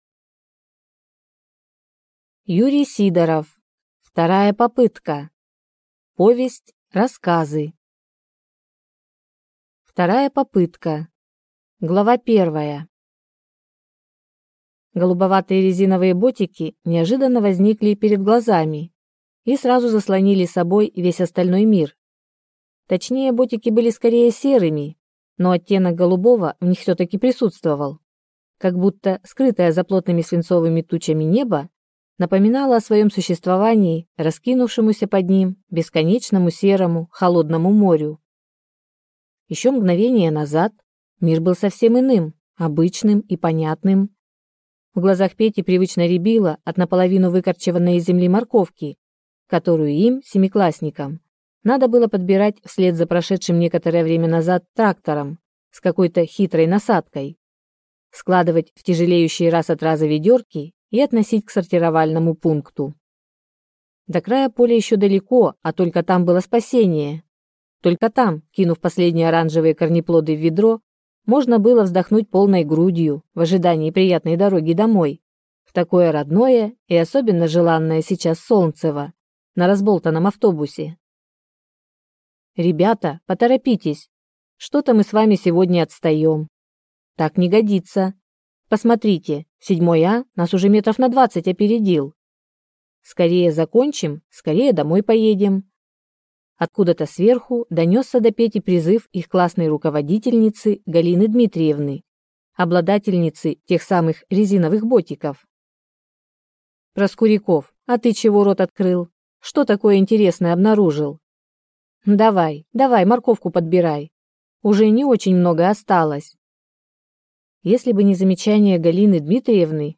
Aудиокнига